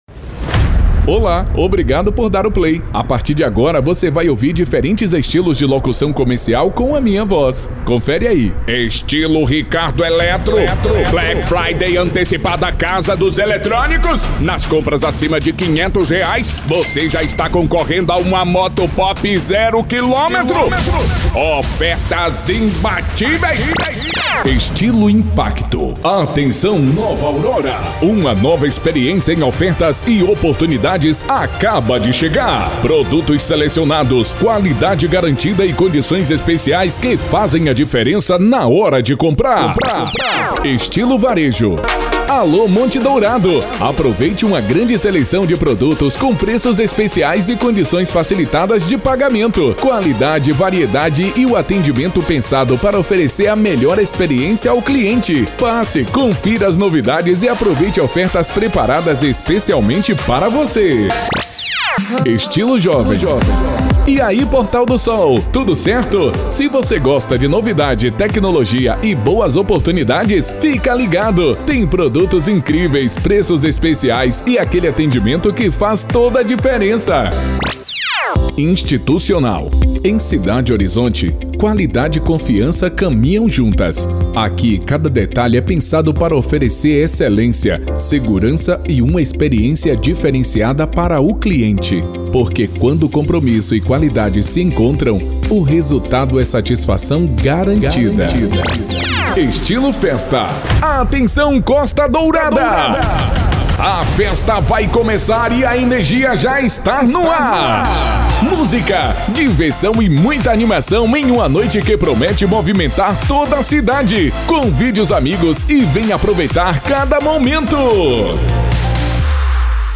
Impacto
Animada